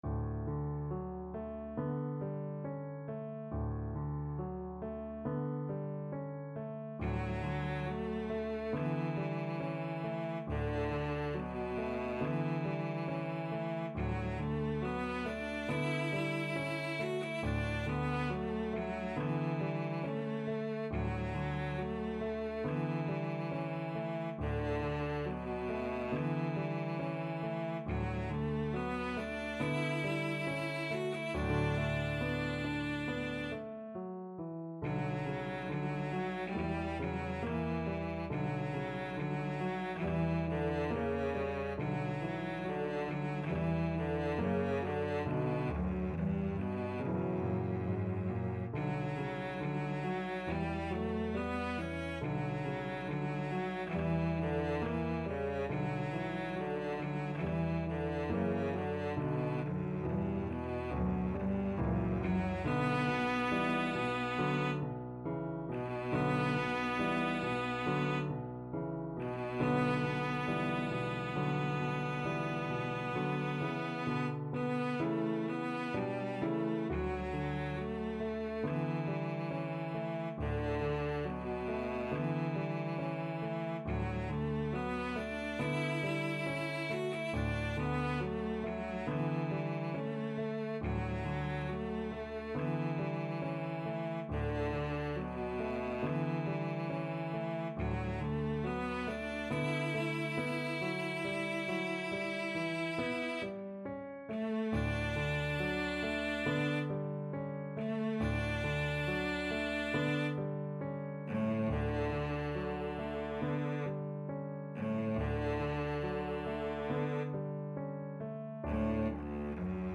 Cello
4/4 (View more 4/4 Music)
D major (Sounding Pitch) (View more D major Music for Cello )
~ =69 Poco andante
Classical (View more Classical Cello Music)